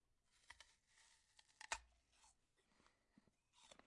多样化 " 咬牙切齿的效果
描述：咬一口咆哮
Tag: 抽象 horro 怪异 恐怖 效果 爬行 咆哮 SFX 科幻 变压器 可怕 声音